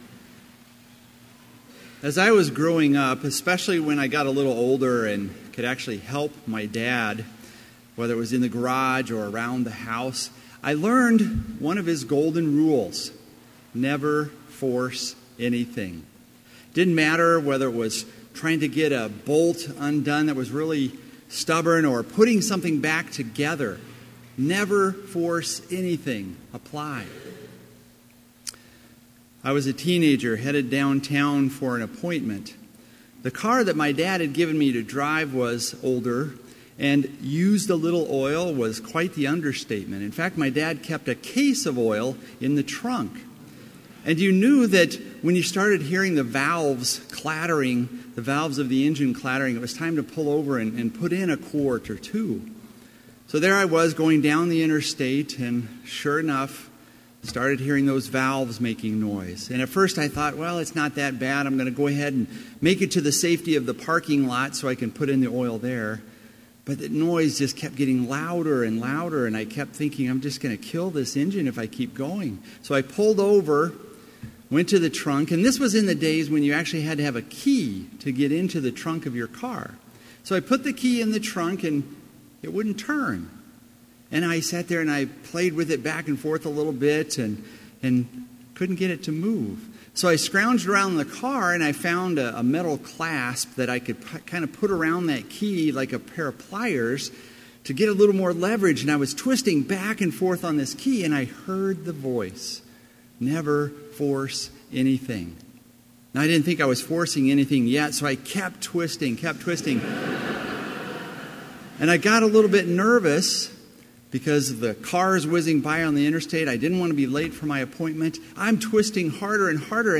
Complete Service
• Devotion
This Chapel Service was held in Trinity Chapel at Bethany Lutheran College on Tuesday, January 10, 2017, at 10 a.m. Page and hymn numbers are from the Evangelical Lutheran Hymnary.